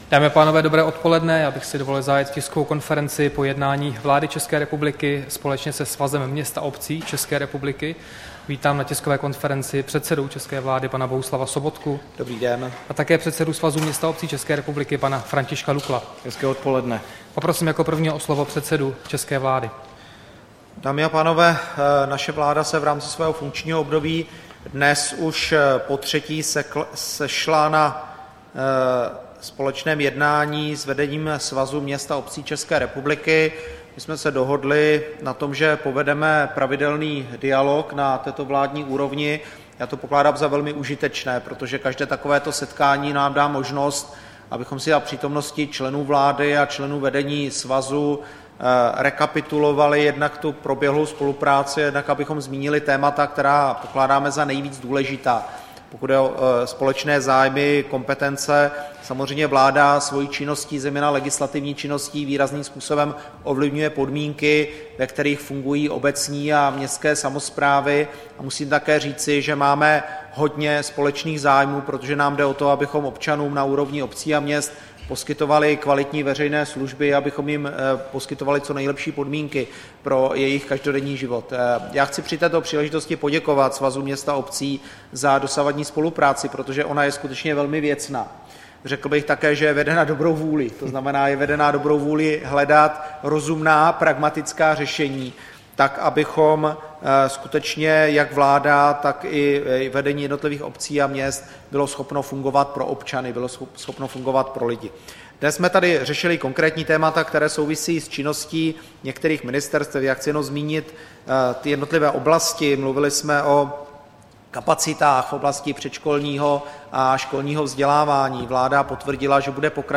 Tisková konference po jednání vlády se Svazem měst a obcí 11. července 2016